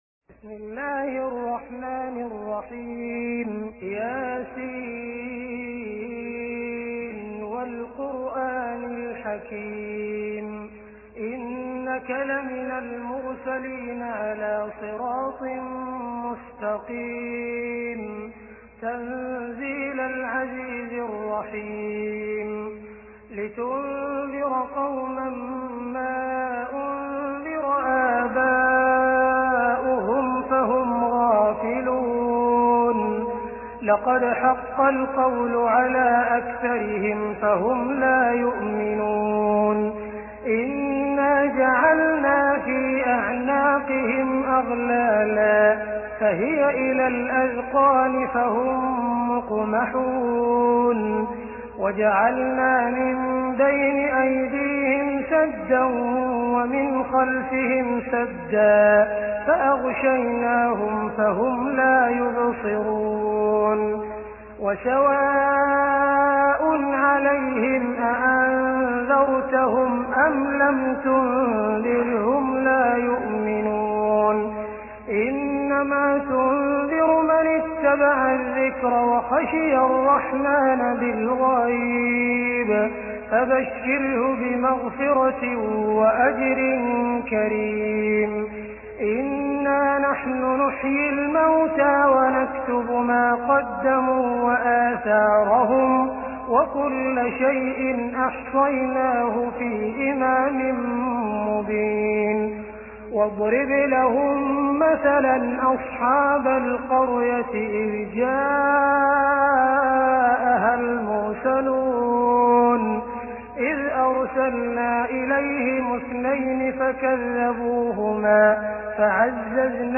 Surah Yaseen Beautiful Recitation MP3 Download By Abdul Rahman Al Sudais in best audio quality.